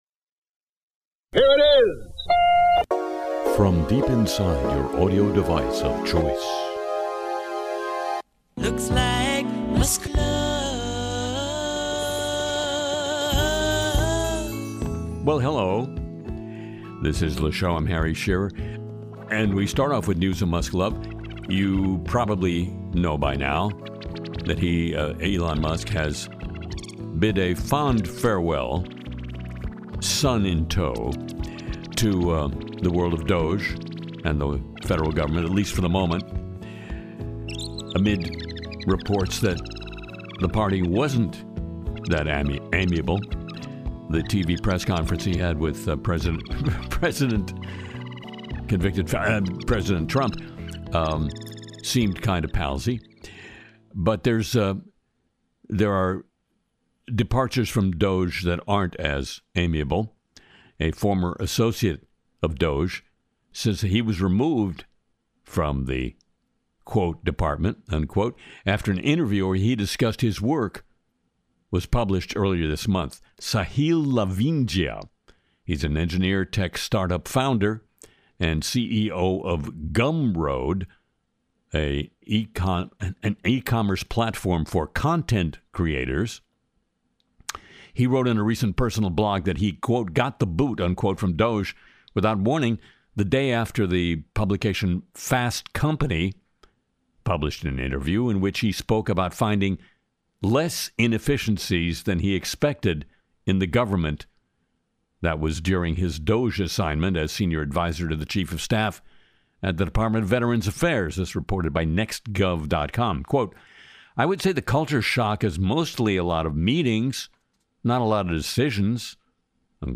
This week on Le Show, Harry Shearer interviews AI expert Gary Marcus, debuts a new Trump satire song, breaks down Elon Musk headlines, and covers the week's sharpest stories.